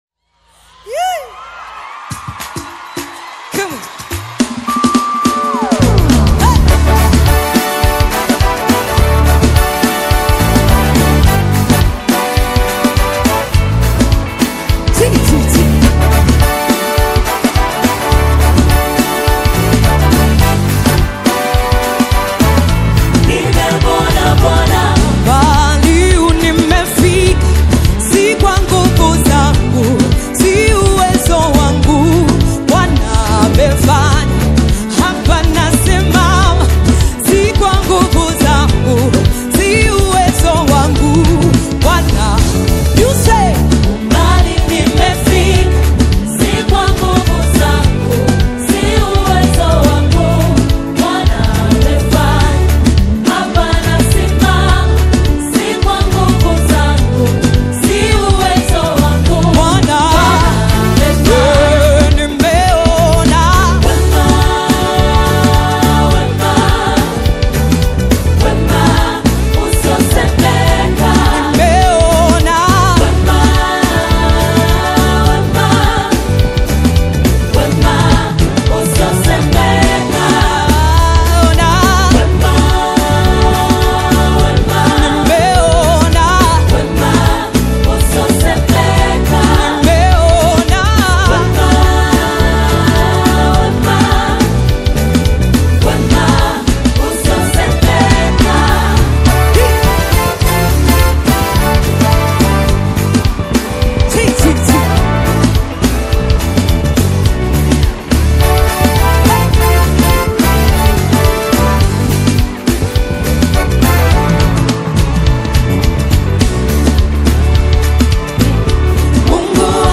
The electrifying and Spirit-filled new single